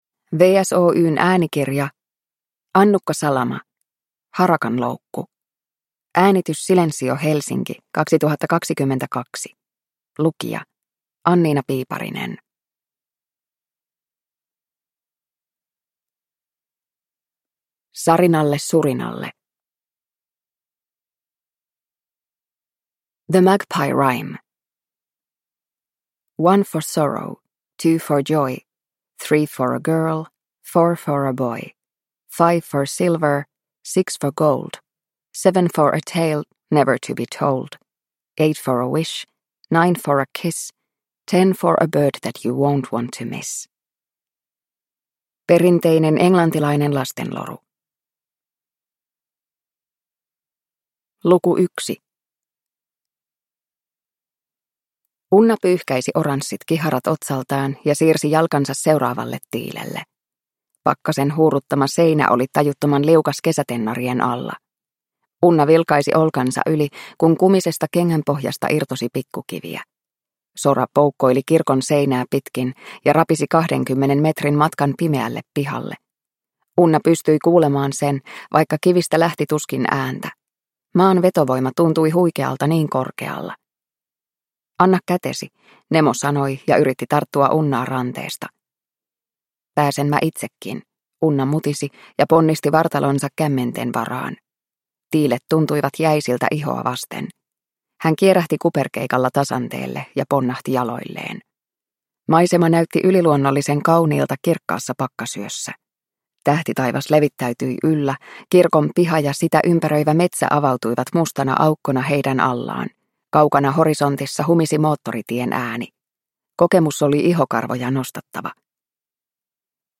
Harakanloukku – Ljudbok – Laddas ner